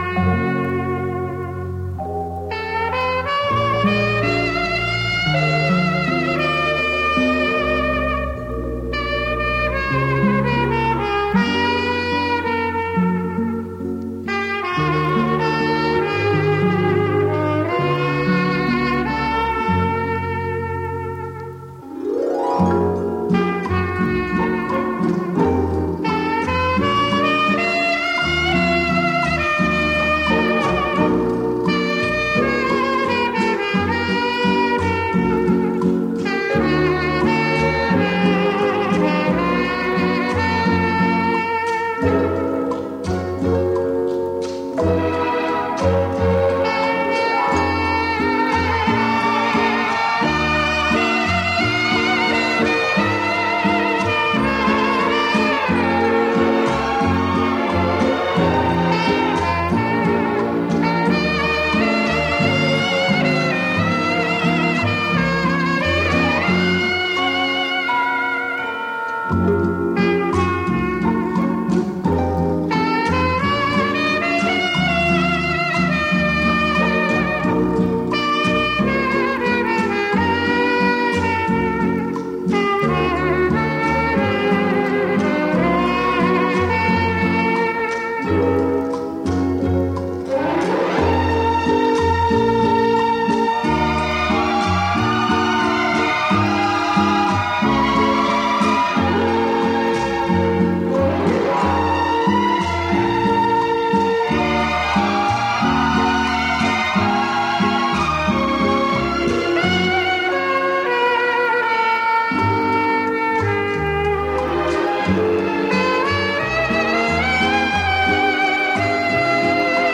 世界名曲激情重现，柔美小喇叭再度出击；